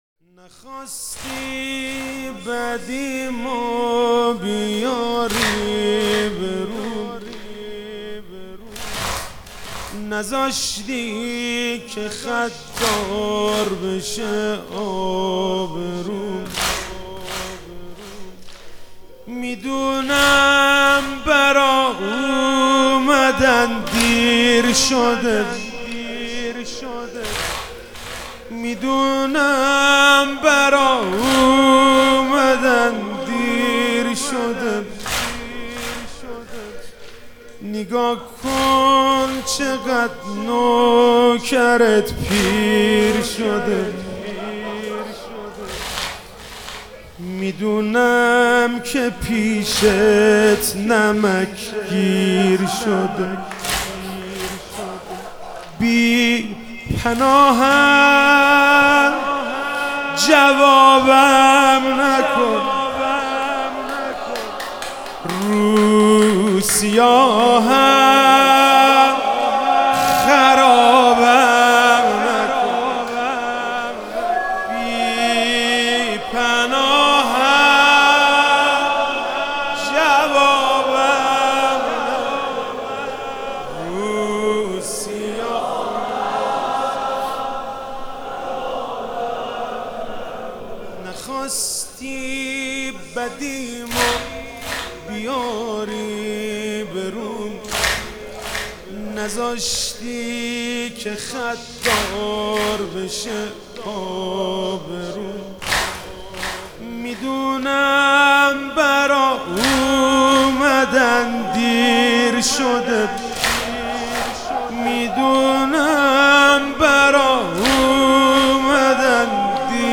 محرم 98 شب پنجم - واحد - نخواستی بدیم و بیاری به روم